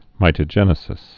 (mītə-jĕnĭ-sĭs)